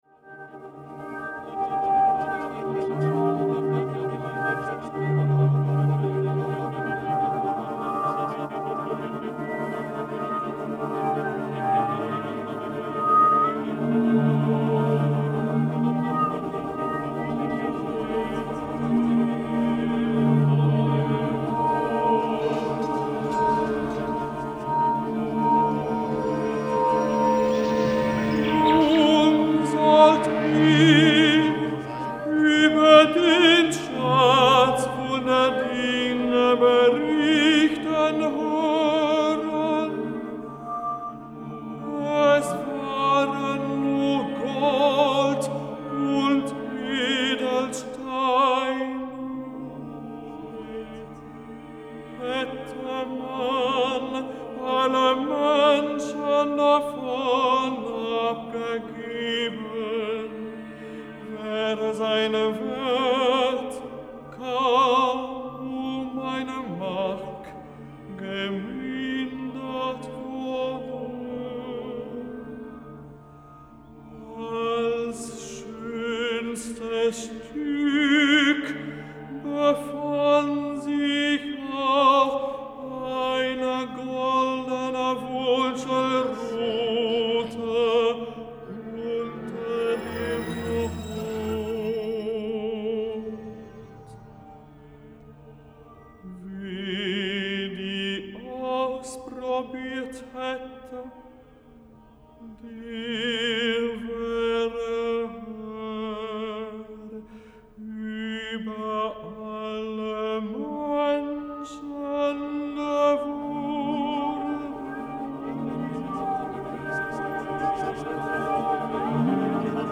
soprano
alto
tenor
bass